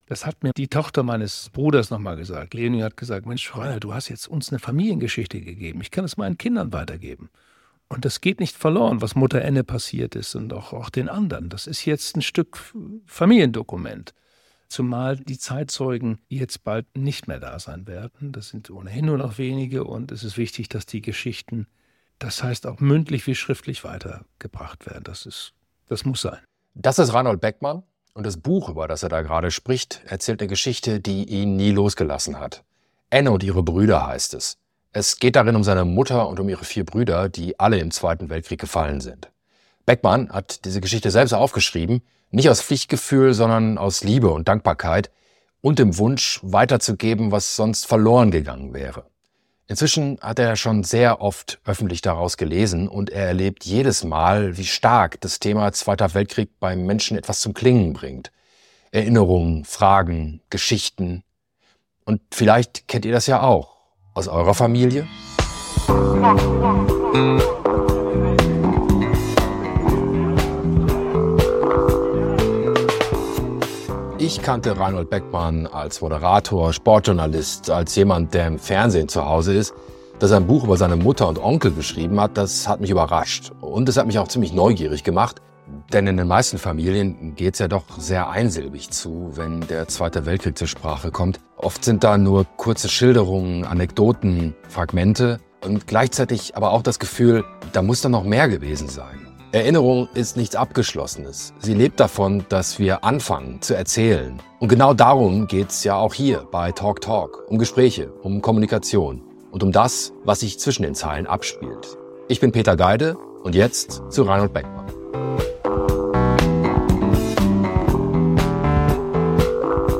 Beschreibung vor 1 Jahr Diesmal ist Moderator, Produzent und Musiker Reinhold Beckmann mein Gast. Das Ende des Zweiten Weltkriegs jährt sich im Mai zum 80. Mal und wir sprechen über das Erinnern.
Ein Gespräch über das, was Krieg in Familien hinterlässt, wie wir das Schweigen brechen und warum uns das gemeinsame Erinnern miteinander verbindet.